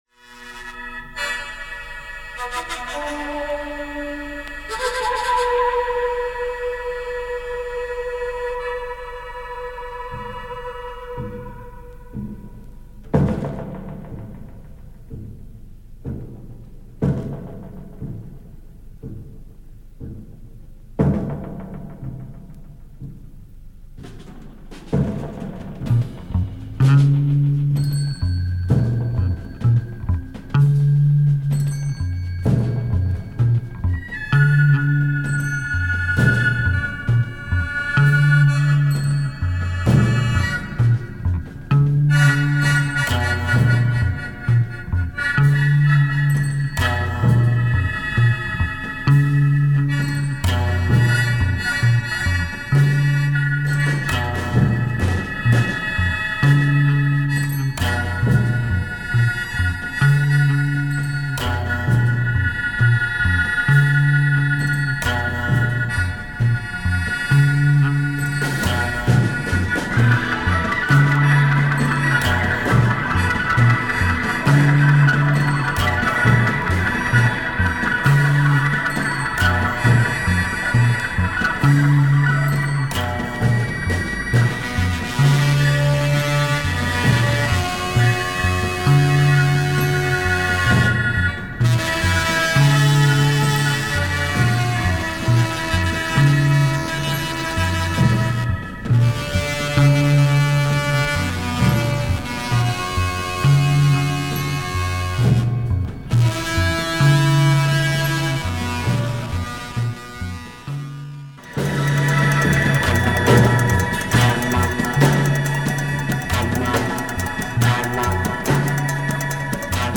bamboo flute
Marvellous Japanese psychedelic groove.